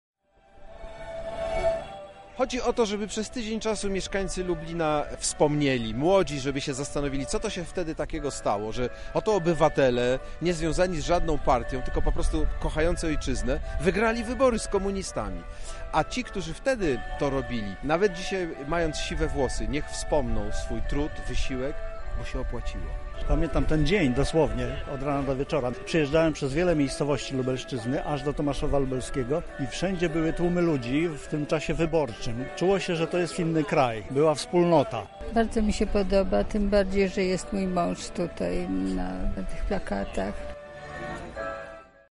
Za nami otwarcie wystawy „29 lat wolności”
Przedstawiała ona historię działalności Komitetów Obywatelskich „Solidarność” w Lublinie i Świdniku. Podczas wernisażu mogliśmy spotkać się i porozmawiać z uczestnikami tamtych wydarzeń.